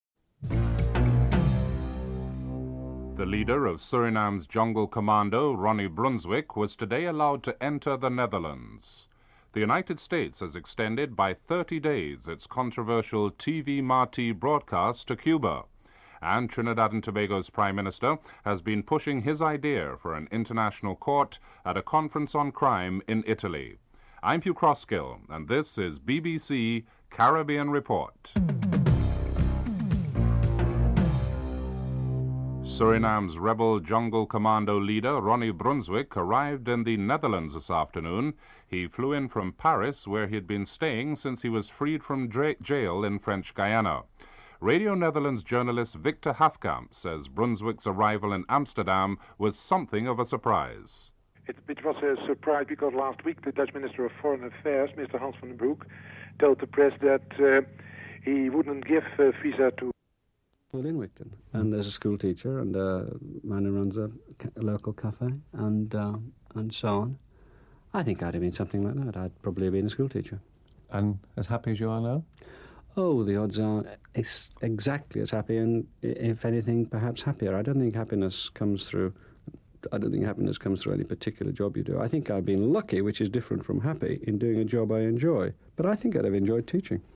Caribbean Report ends abruptly during the first segment and the rest of the clip features Hunter Davies and the Arts Programme.
3. Hunter Davies and the Arts Programme. Interview with Melvyn Bragg and music features (01:00-16:32)